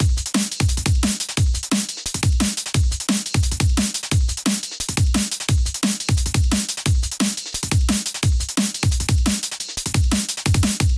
DNB 175 邦戈节拍循环
描述：NB 175 邦戈拍子循环
标签： 175 bpm Drum And Bass Loops Drum Loops 1.85 MB wav Key : Unknown
声道立体声